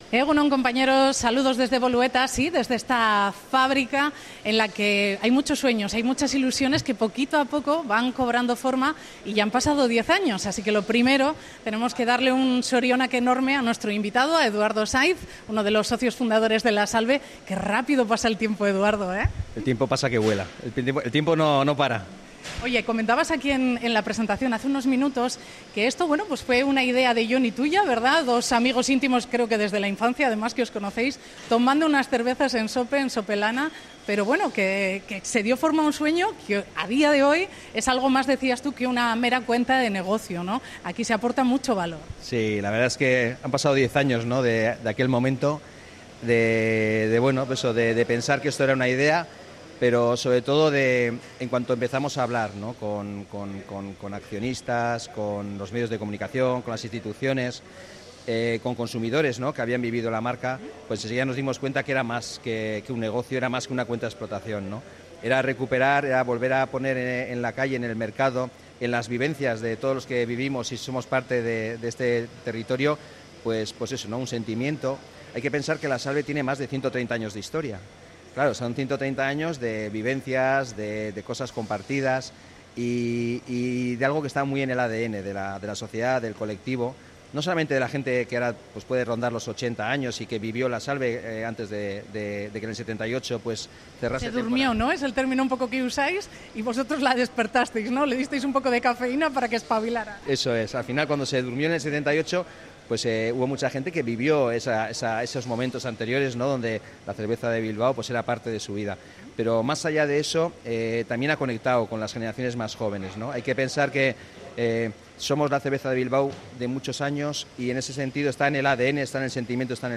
entrevistado en ONDA VASCA